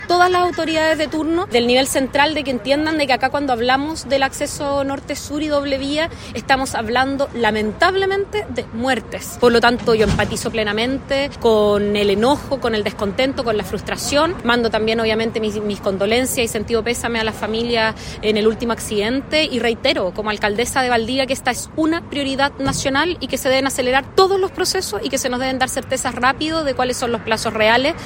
Declaraciones de la alcaldesa de Valdivia, que se suman a las críticas de senadores de Los Ríos, exigiendo fechas claras para avanzar con el proyecto y acusando que el Ministerio de Obras Públicas ha mentido anteriormente.